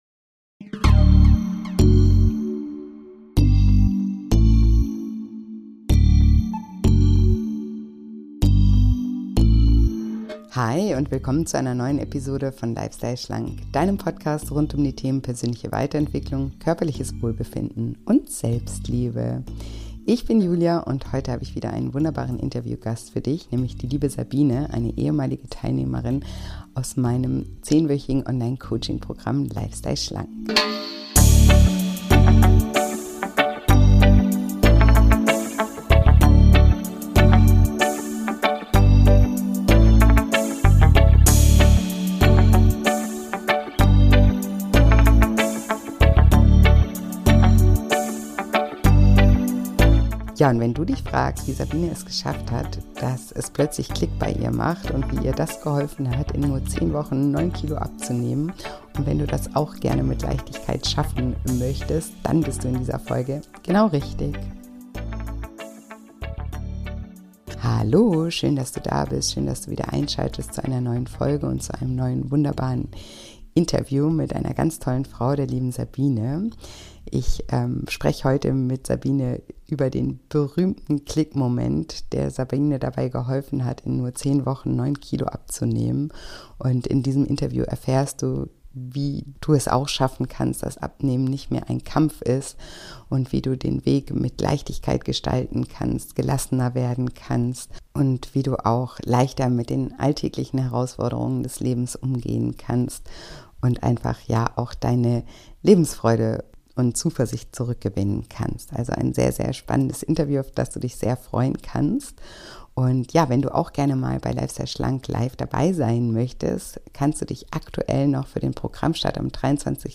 Im diesem Interview lernst du: Wie du es schaffen kannst, dass es auch bei dir „klick“ im Kopf macht. Wie du ohne Verzicht und dafür mit Freude abnehmen kannst.